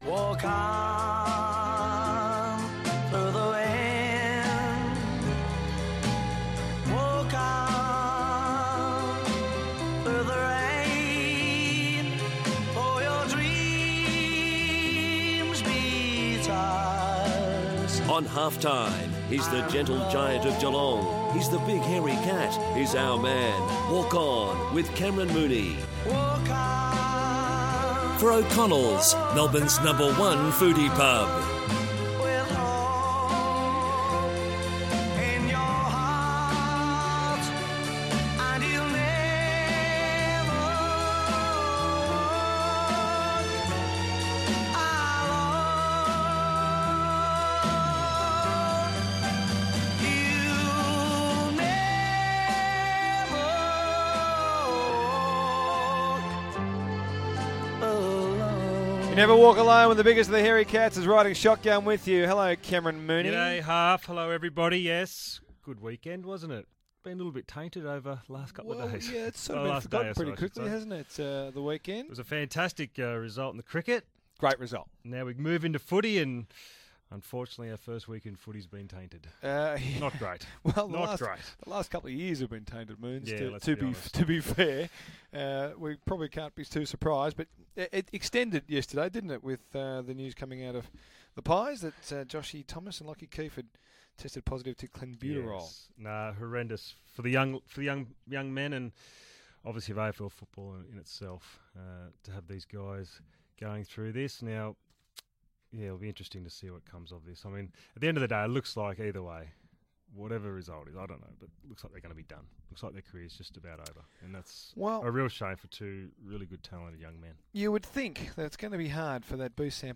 Cameron Mooney joins Daniel Harford in the studio ahead of the opening round of AFL action for 2015.